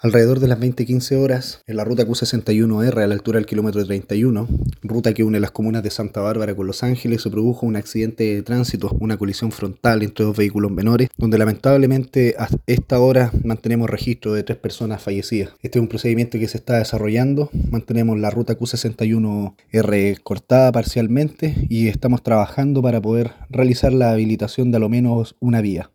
accidente-santa-barbara-carabineros.mp3